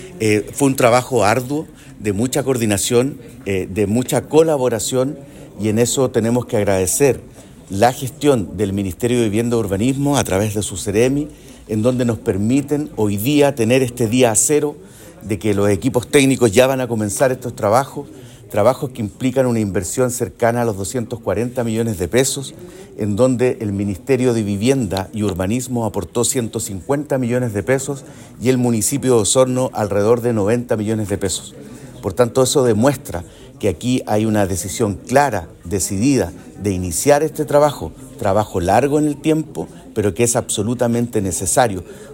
Por su parte, el Alcalde (S), Claudio Villanueva, destacó que este proceso es un hito para la ciudad y se espera que marque un cambio positivo en la planificación territorial.